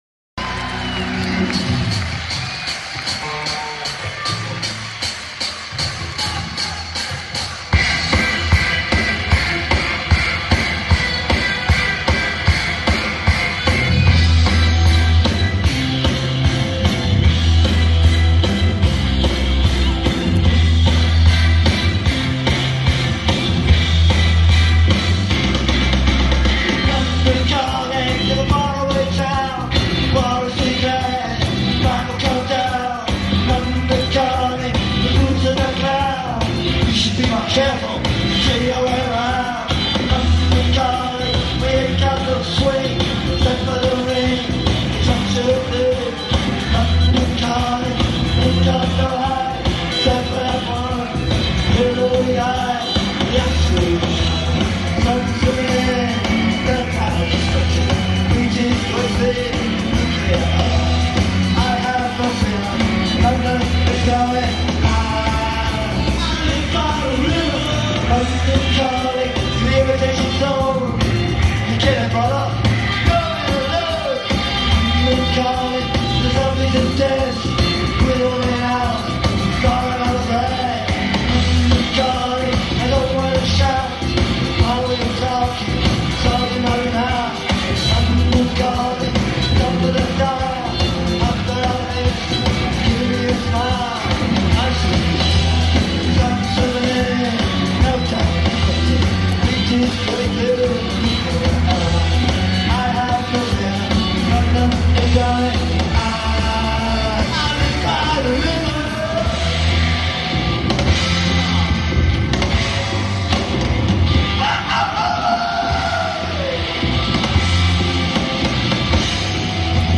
Hammersmith Punk